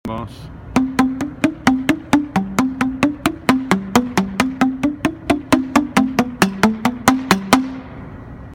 Nowy instrument z rodziny aerofonów.
Gra się na nim uderzając ręką lub specjalną paletką. Dźwięk jaki wydają Handpipes jest tak zaskakujący i oryginalny w swej barwie, że zawsze wywołuje pozytywne emocje.
Diatoniczna
G3 – C5
Handpipes.mp3